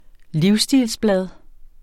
Udtale [ ˈliwsˌsdiːls- ]